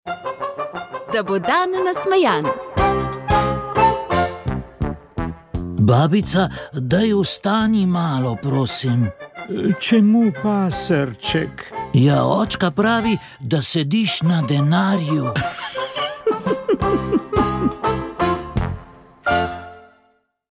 Kako naj se obnašamo pri spremljanju prenosa svete maše prek spleta? Kaj bi rekel tistim, ki dvomijo o virusu? Na vprašanja je odgovarjal predsednik slovenske škofovske konference, ljubljanski nadškof Stanislav Zore.